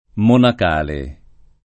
[ monak # le ]